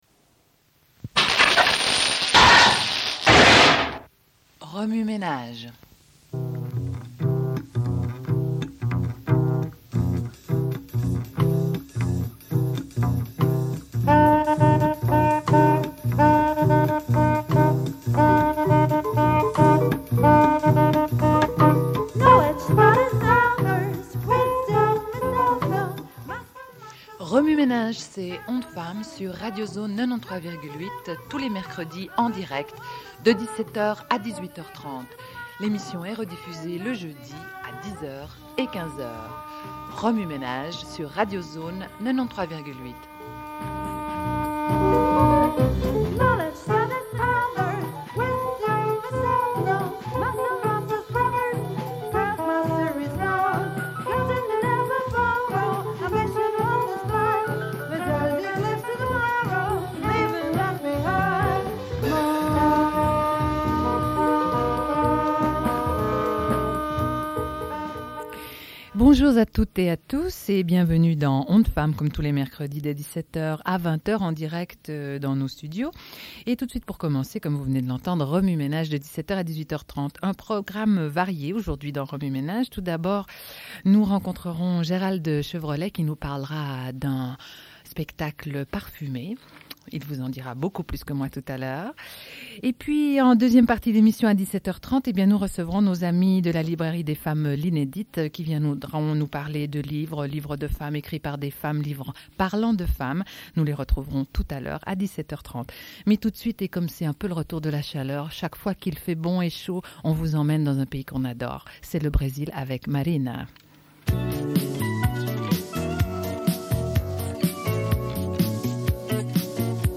Une cassette audio, face A31:23